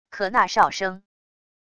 可那哨声wav音频